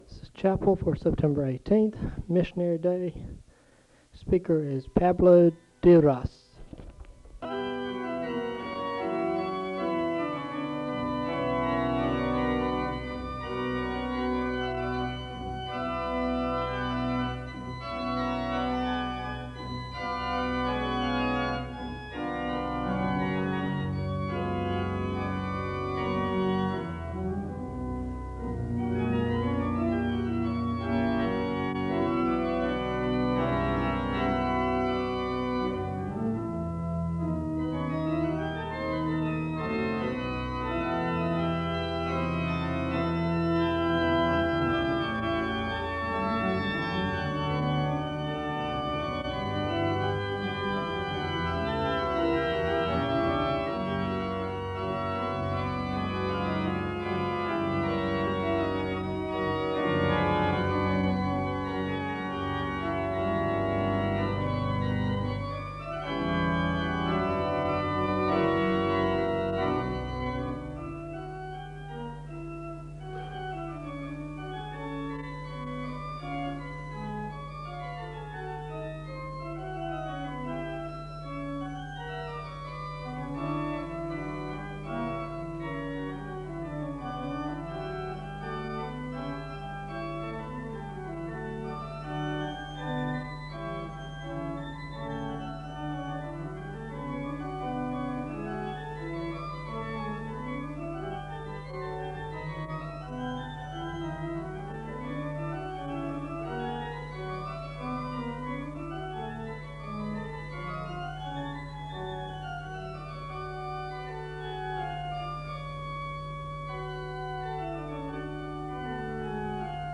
The service begins with organ music (00:00-06:26). The speaker delivers a reading from the Psalms, and he gives a word of prayer (06:27-08:58).
The choir sings the anthem (14:20-17:14).